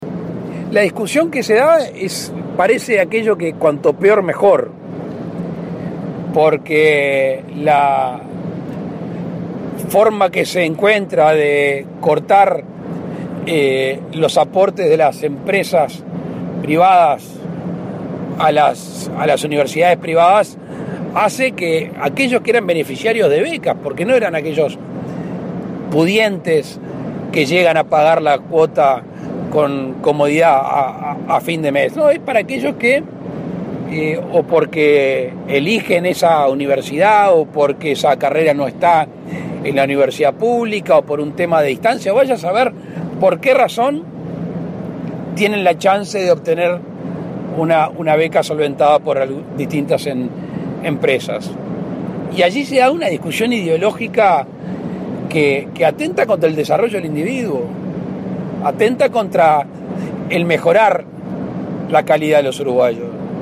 En su audición semanal, Lacalle se refirió a la Rendición de Cuentas que prepara el gobierno y opinó que se gastó mal, poniendo como ejemplo lo que calificó "el despilfarro de Pluna y Ancap".